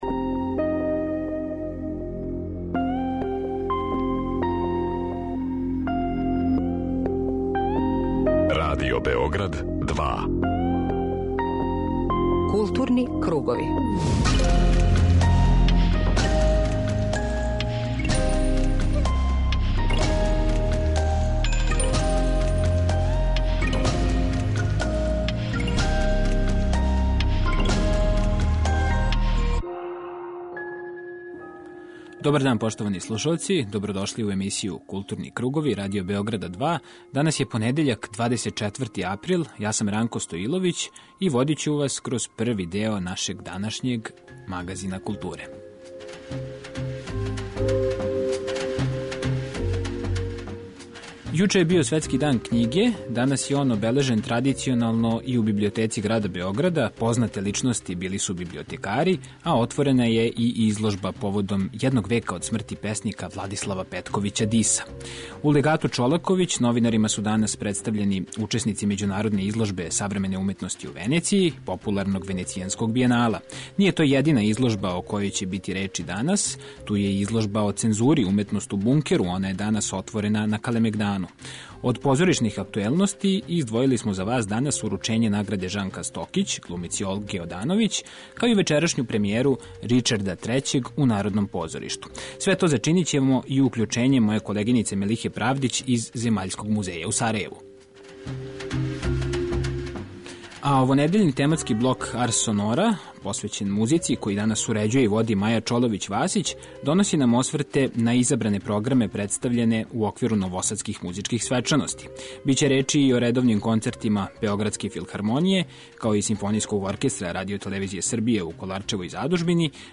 Чућете и део трибине Нови звучни простори, на којој је представљено дело Списак бр. 1, Зорана Ерића, за виолу соло.
преузми : 40.05 MB Културни кругови Autor: Група аутора Централна културно-уметничка емисија Радио Београда 2.